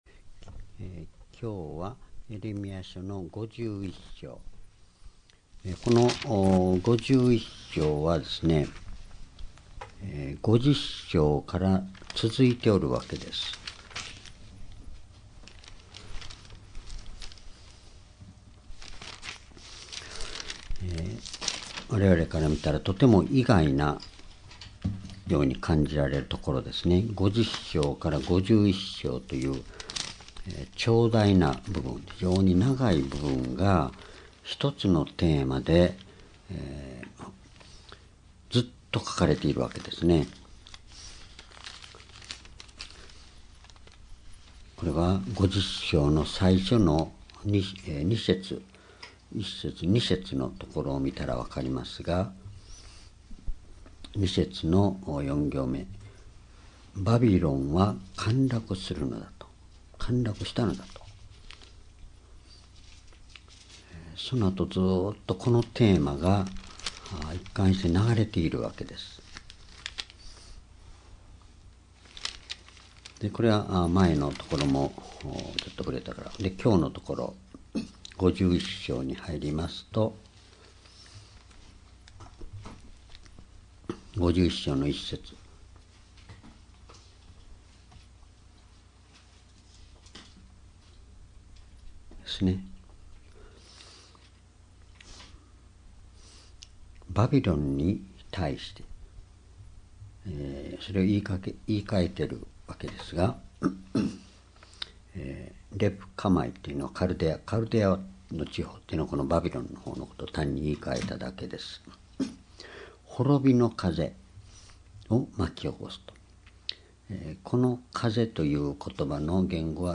主日礼拝日時 2018年2月6日 夕拝 聖書講話箇所 「悪の力への裁き」 エレミヤ51章 ※視聴できない場合は をクリックしてください。